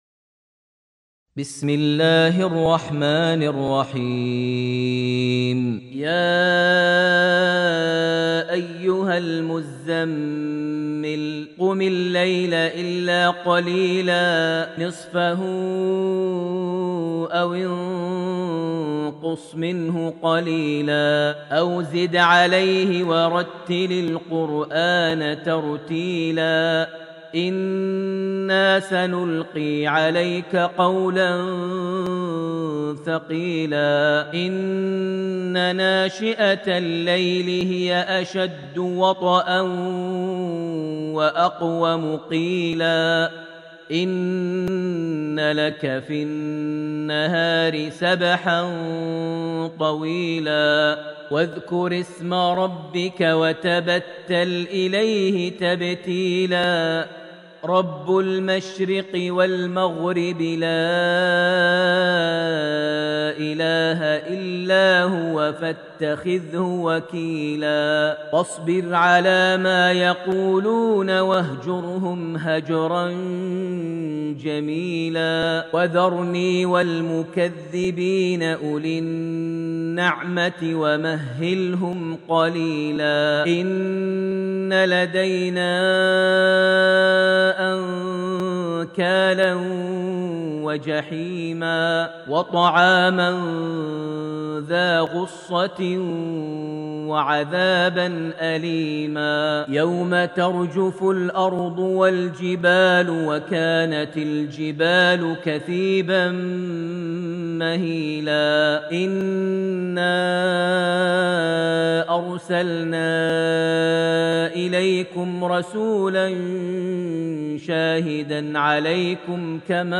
Surat Almuzamil > Almushaf > Mushaf - Maher Almuaiqly Recitations